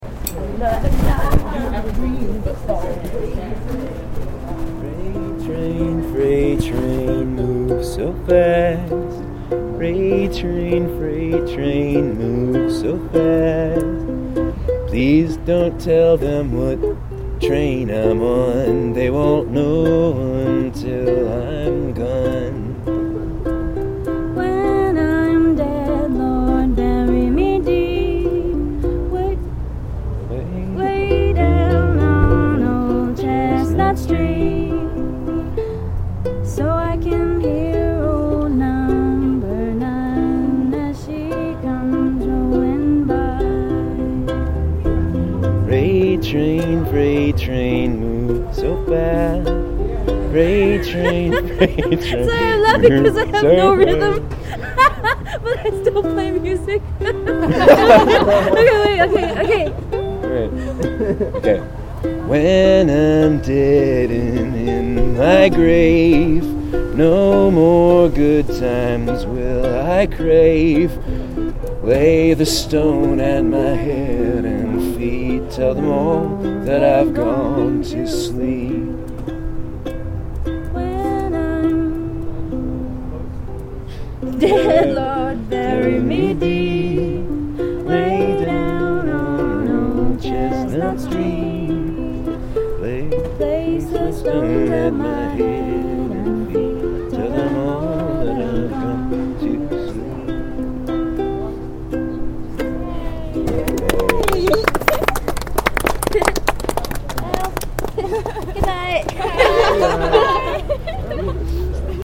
in Santa Clara